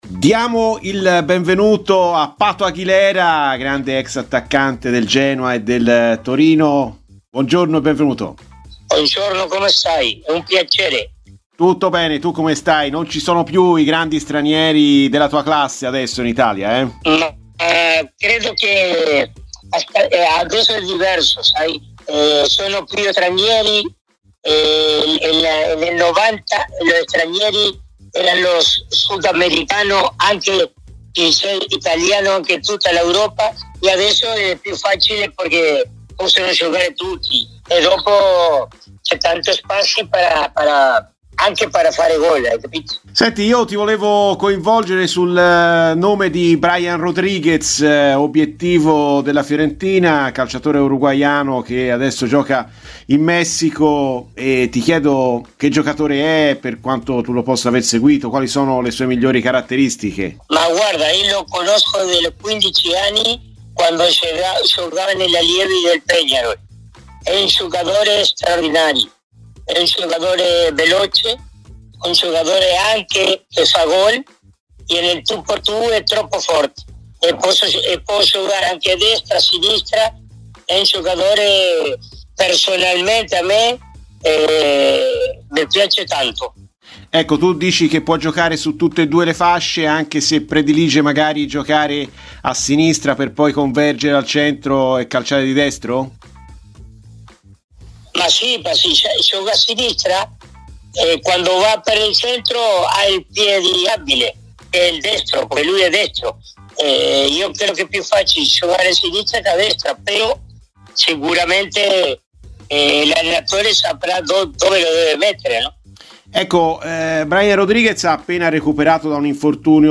Carlos Pato Aguilera, ex attaccante uruguaiano con un passato in Serie A, è intervenuto a Radio FirenzeViola durante "Viola Amore Mio" per parlare del connazionale Brian Rodriguez entrato nelle mire della Fiorentina: "Può giocare su tutte e due le fasce anche se lui predilige quella di destra.